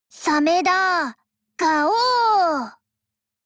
Cv-40808_warcry.mp3